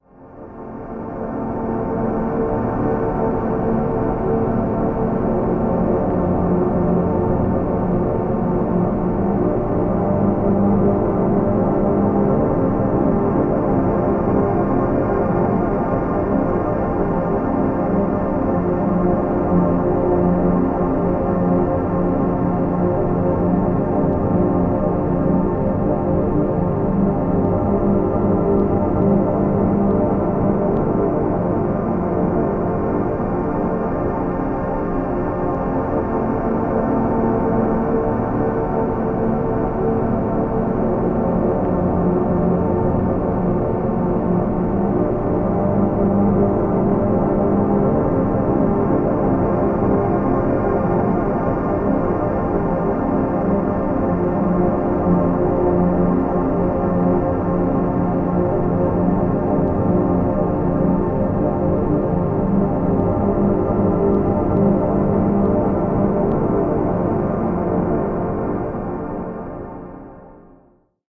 BGM
Speed 90%